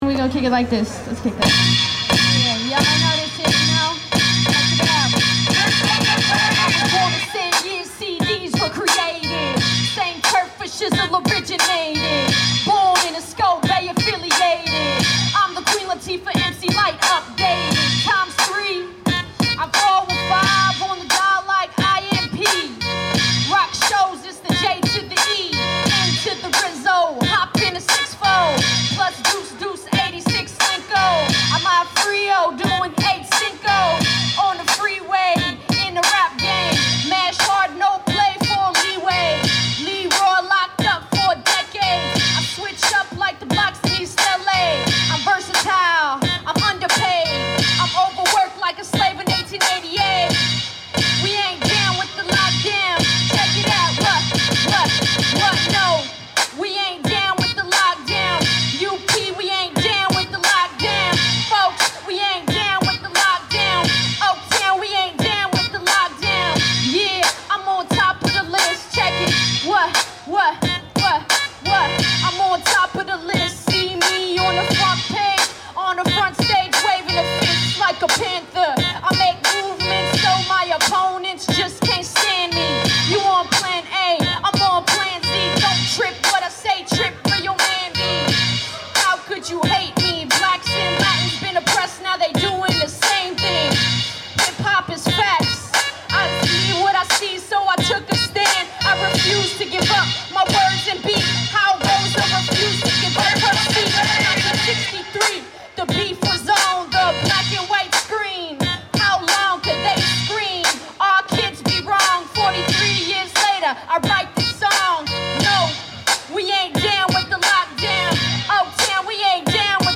NOT DOWN WITH THE LOCKDOWN! Hip Hop Show and Rally to Close the CYA Youth Prisons in Downtown Oaktown
hiphoprally_7-16-05.mp3